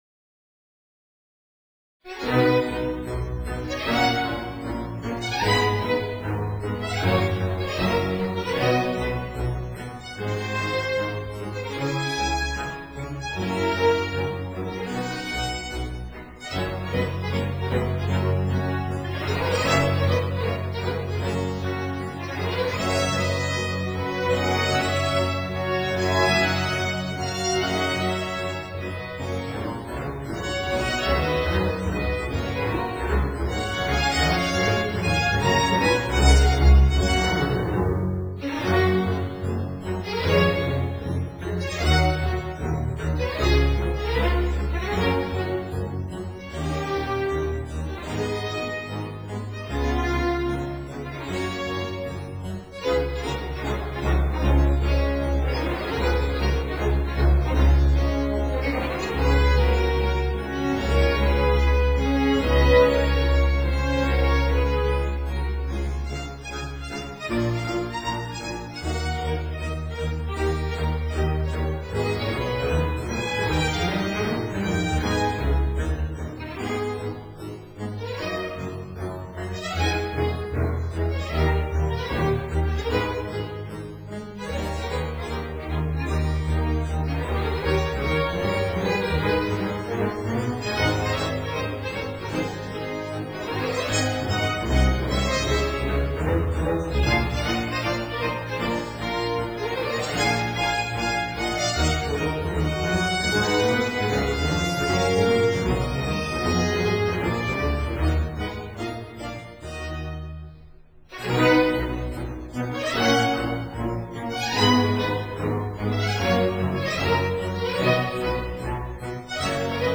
所屬時期/樂派： 巴洛克威尼斯樂派
Sinfonia for Strings, RV 146, RV 149
(Period Instruments)